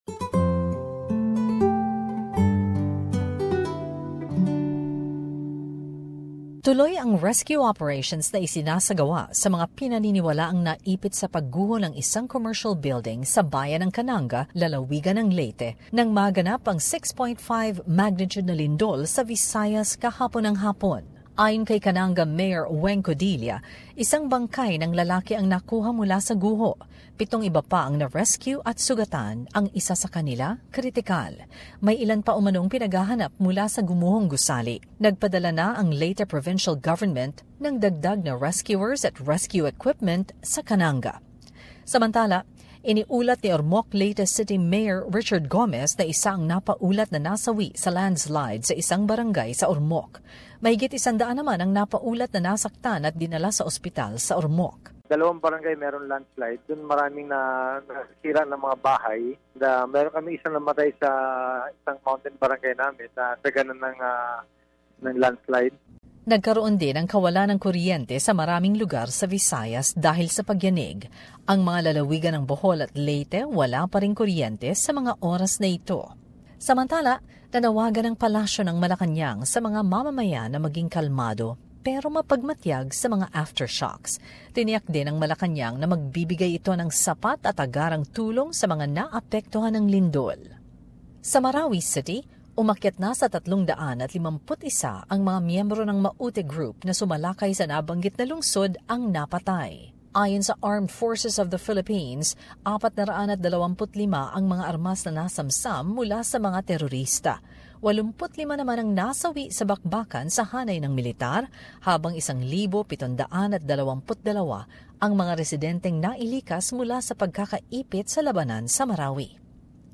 Part of the news from Manila